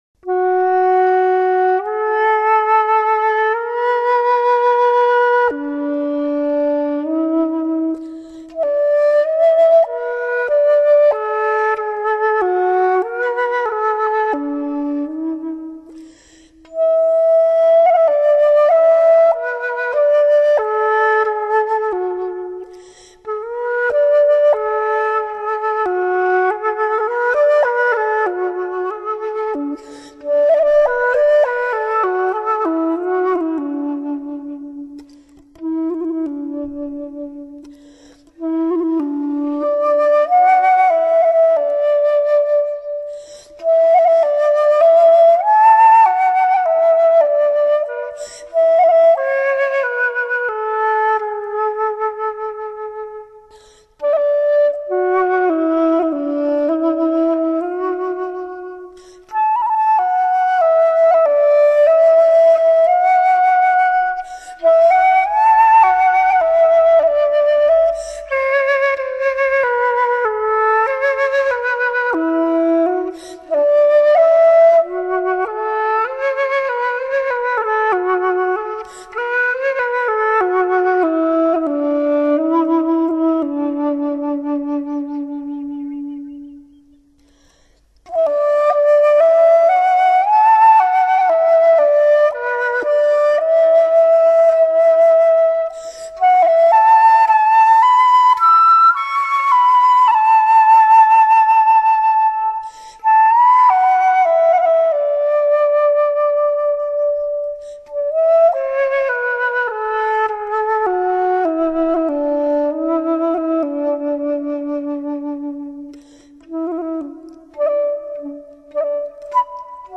音色柔和，甘美幽雅，清虚淡远。一种空灵到极致、飘渺到极致、凄美到极致的天籁神音，让聆者无不动容！